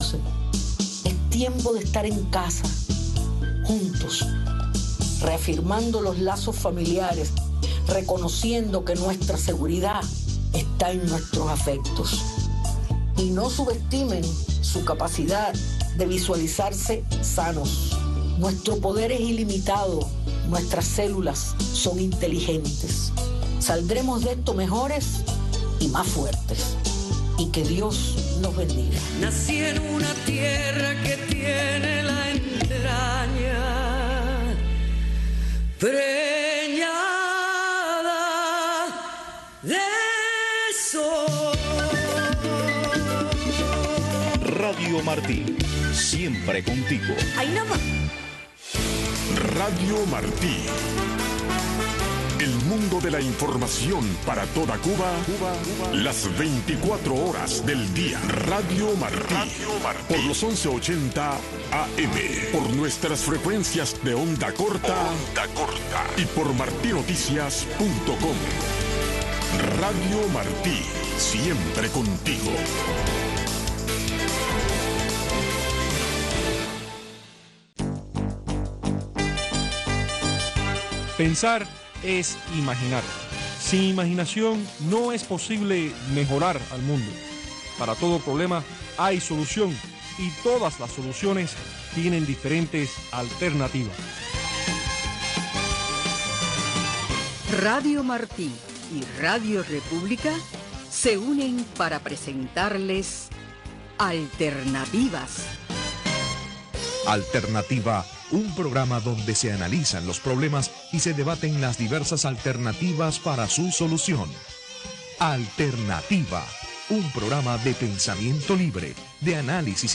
El programa radial Alternativa es un programa de panel de expertos